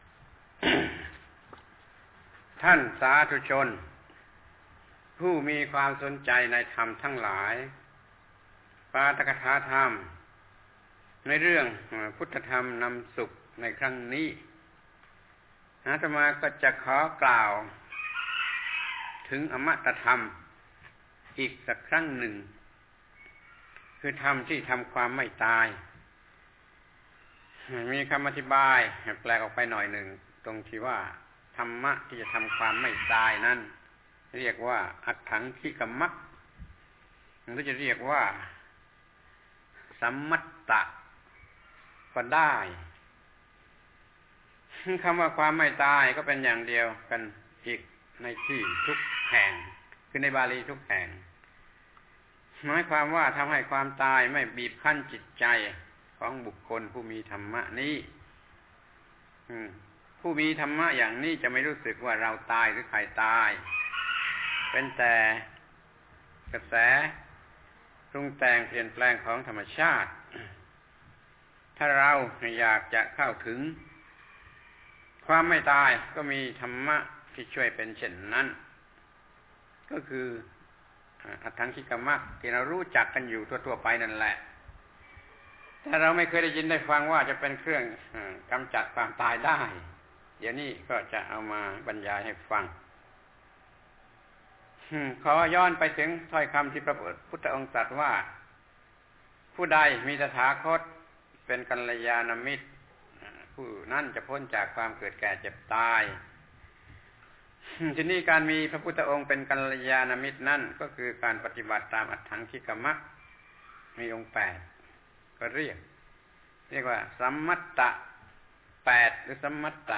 พระธรรมโกศาจารย์ (พุทธทาสภิกขุ) - ปาฐกถาธรรมทางโทรทัศน์สุราษฯและหาดใหญ่ รายการพุทธธรรมนำสุข ครั้ง ๕๓ อมตะธรรมมีได้ด้วยอัฏฐังคิกมรรค